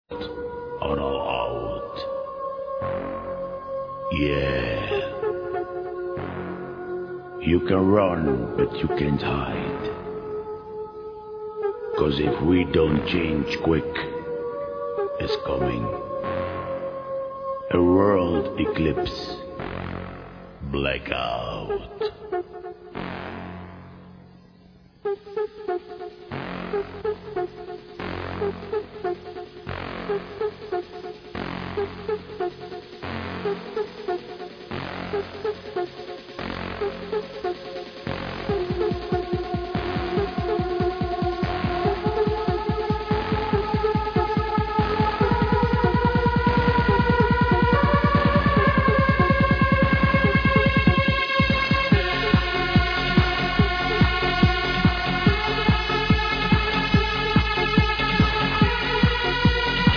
Sounds older than 2001 to me.....
sounds german as well